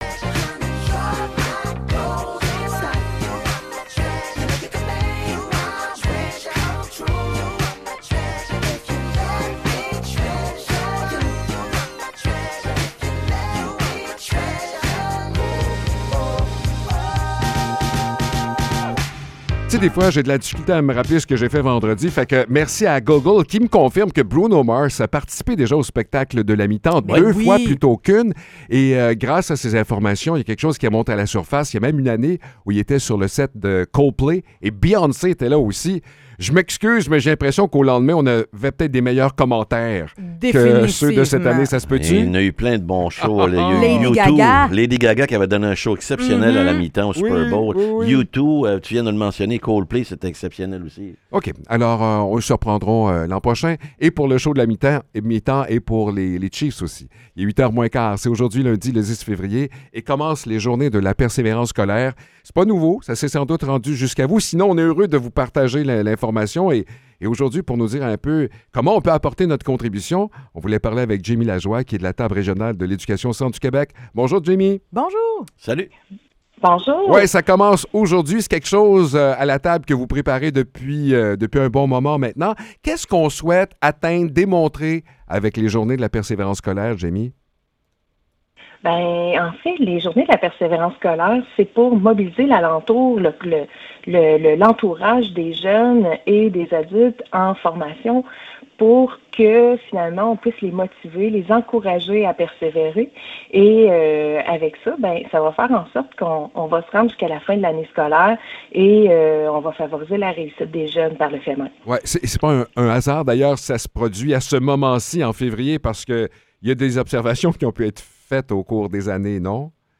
Entrevue pour les Journées de la persévérance scolaire